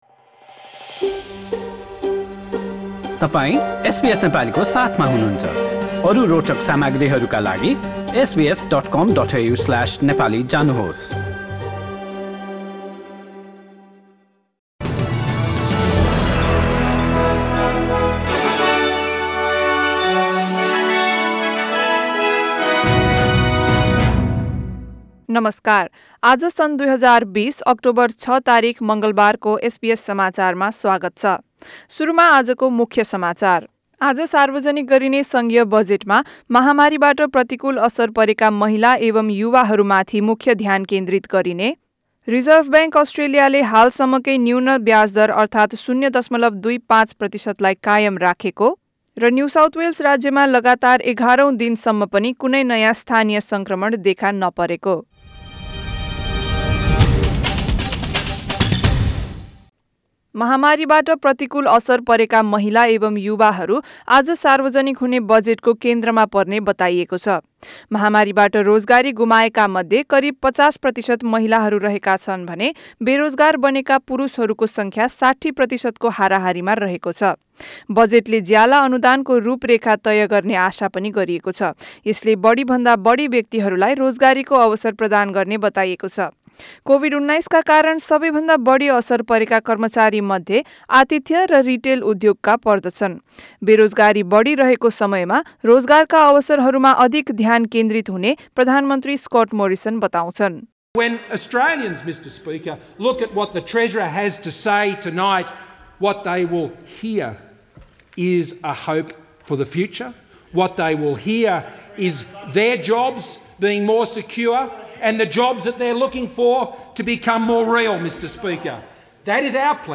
Listen to the latest news headlines in Australia from SBS Nepali radio. In this bulletin: women and young people expected to be a central focus of the federal budget and interest rates have remained on hold at a record-low level of 0.25 per cent.